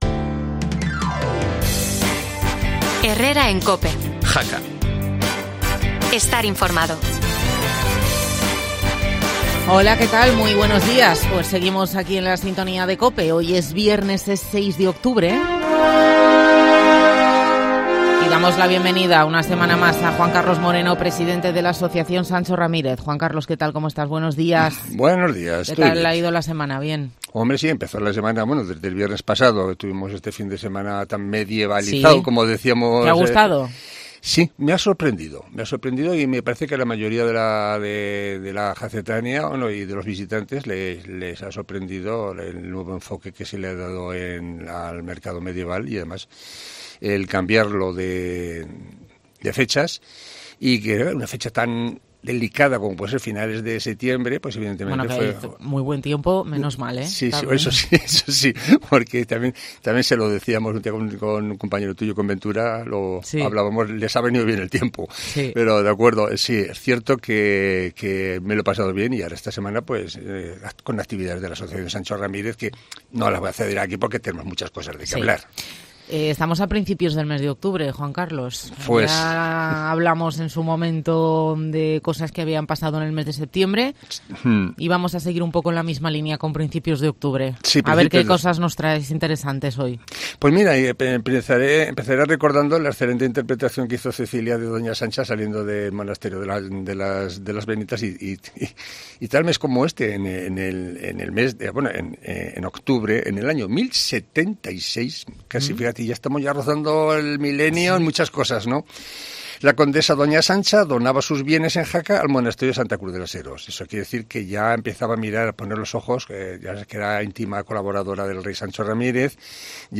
No te pierdas la entrevista en COPE